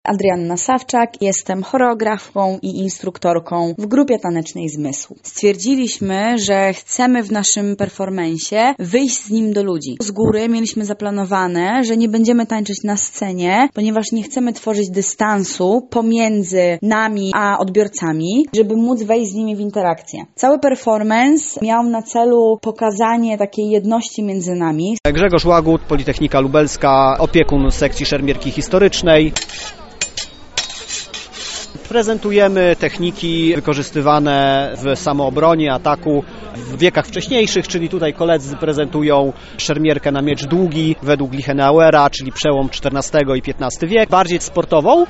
Przed Centrum Spotkania Kultur odbył się Lubelski Piknik Naukowy.